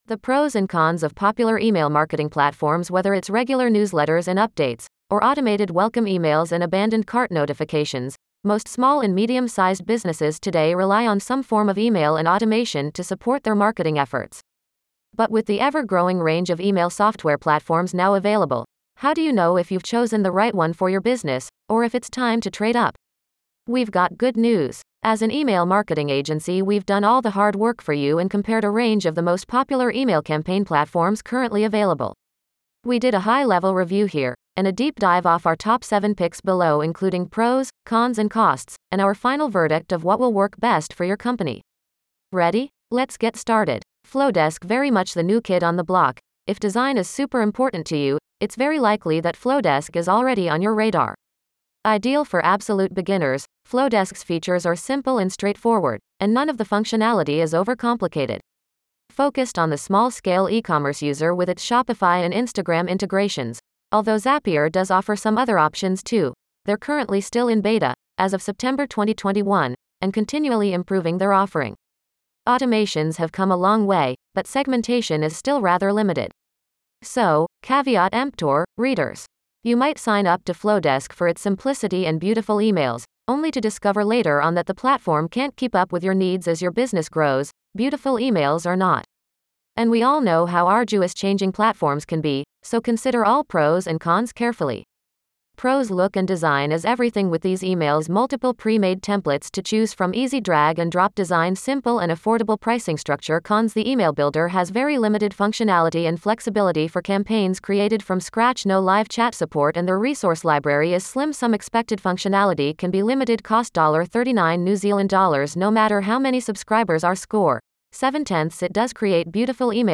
On the go? Listen to a voice recording: